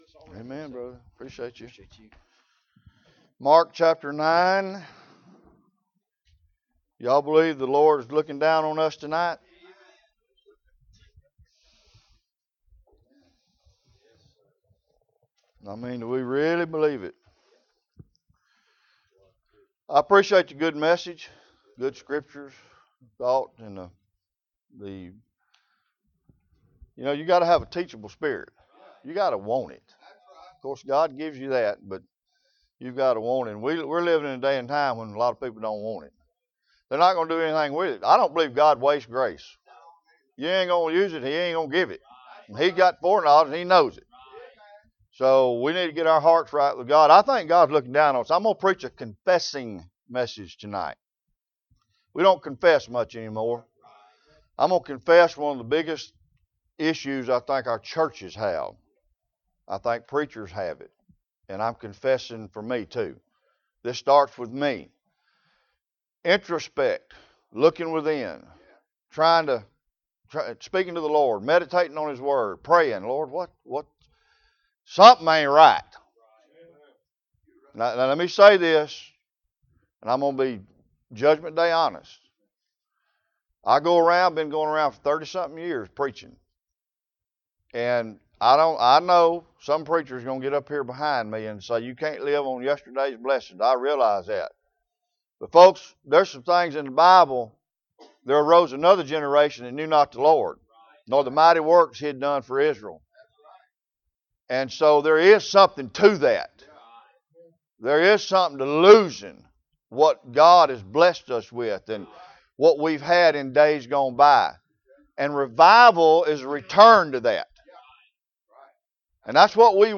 2021 Bible Conference Passage: Mark 9:14-29 Service Type: Bible Conference « What are you going to do with what you know?